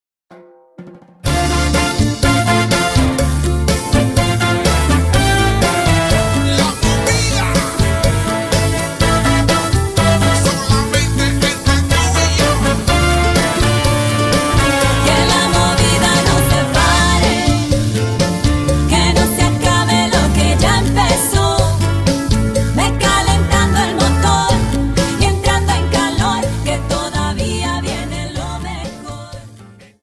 Dance: Cha Cha Song